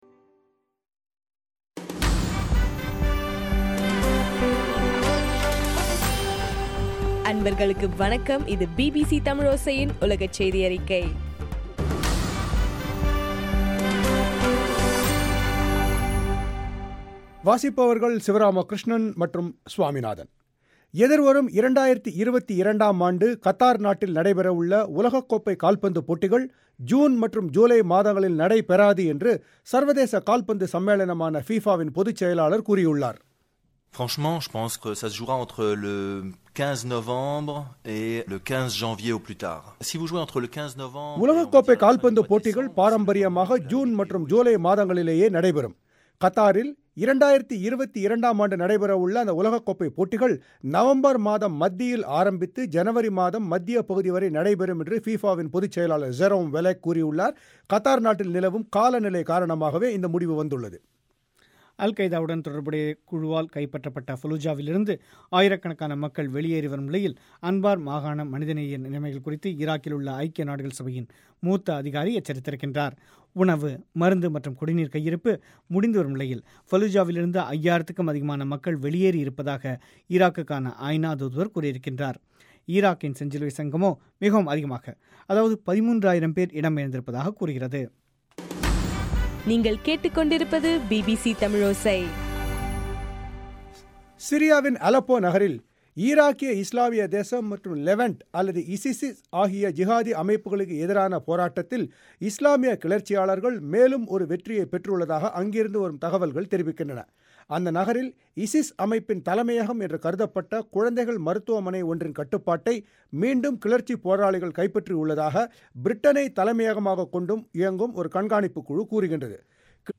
ஜனவரி 8 2014 பிபிசி தமிழோசையின் உலகச்செய்திகள்